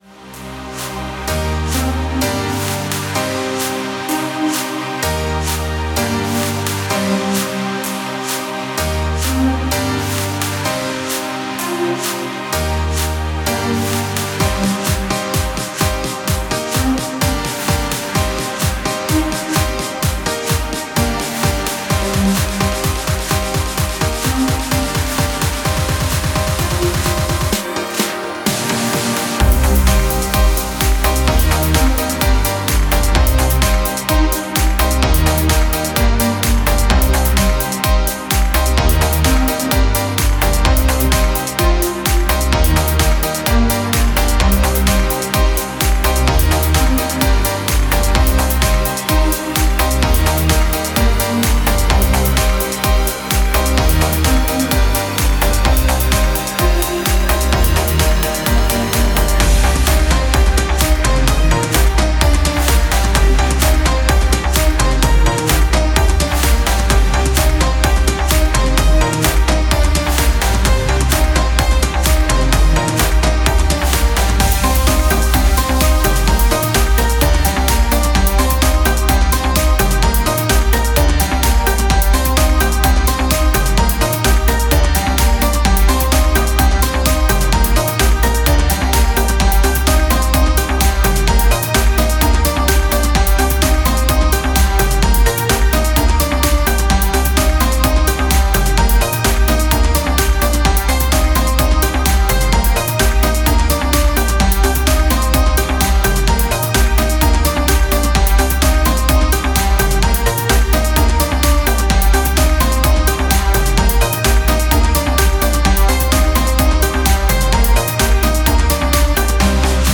Musique dynamique libre de droit pour vos projets.